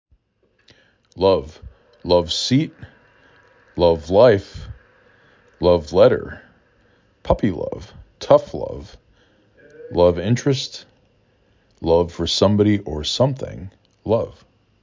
luh v